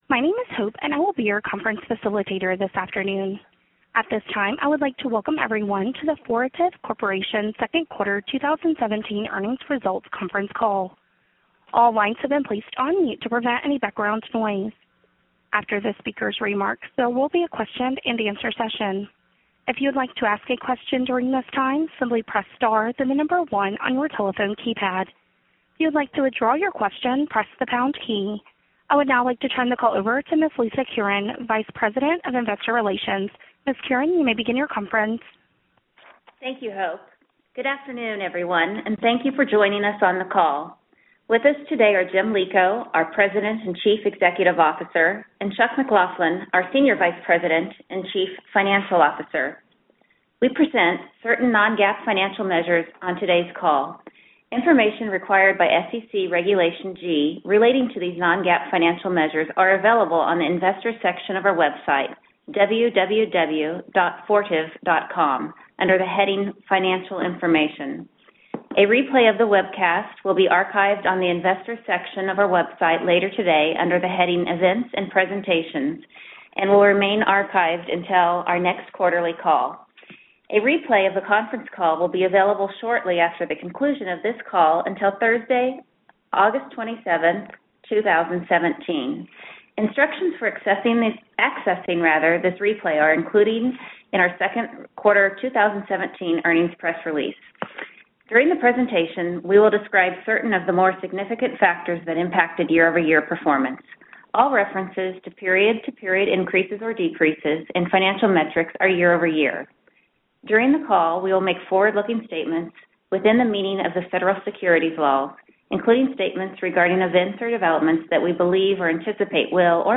Earnings Webcast Q2 2017 Audio
FTV_2Q17_Earnings_Call_Replay.mp3